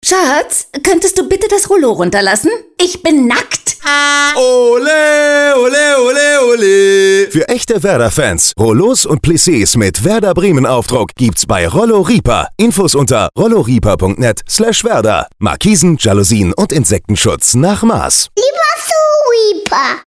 Seit letztem Montag laufen wieder neue Werbespots in Bremen’s meistgehörten Radiosendern Radio Bremen 1 (Hansewelle) und Bremen 4.
Diesmal werden über witzige Geschichten von zwei Eheleuten die neuen Werder Bremen Rollos beworben, die Rollo Rieper erst kürzlich im Programm aufgenommen hat.